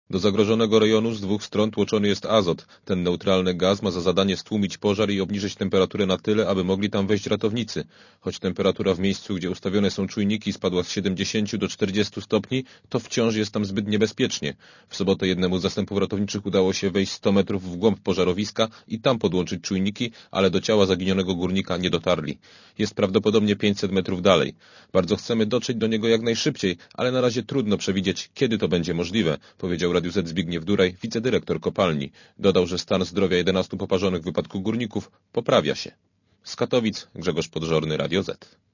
Relacja reportera Radia Zet (150Kb)